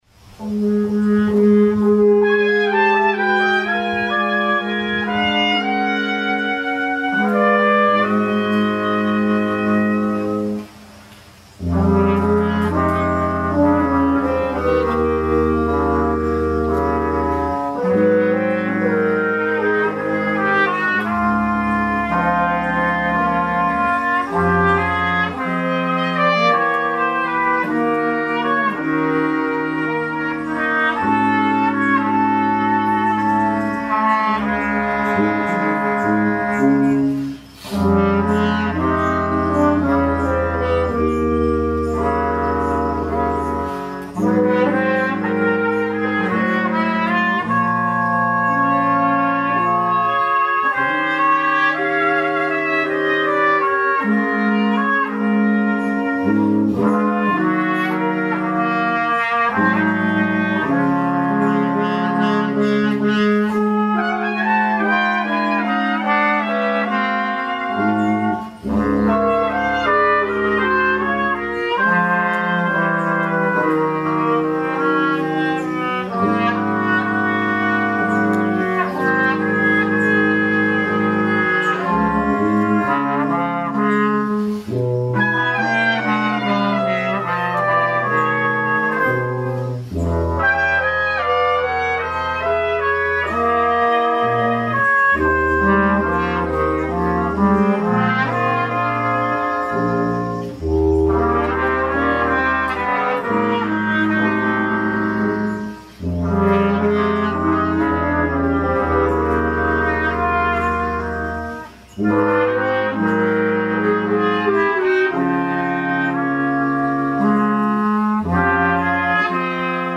for Brass Quintet